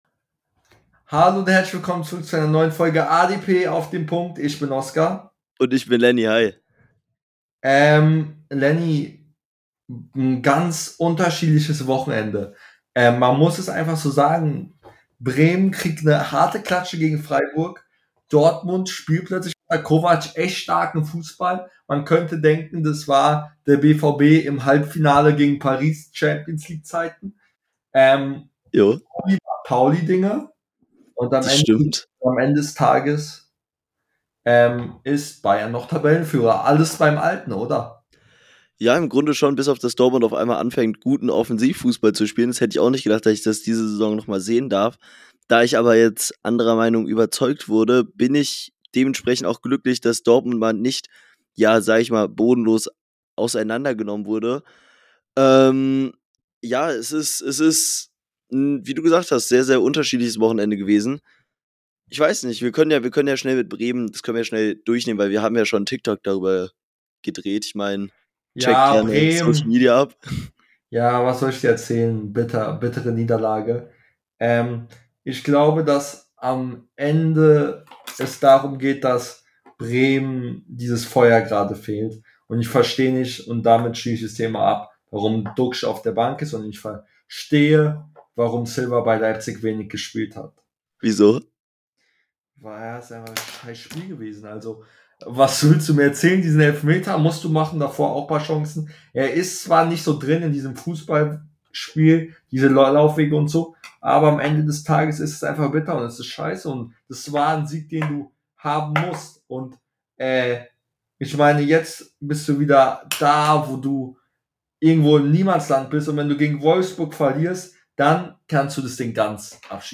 In der heutigen Folge reden die beiden Hosts über Bremens Klatsche , Dortmunds Kantersieg , St.Paulis Stürmerproblem und vieles mehr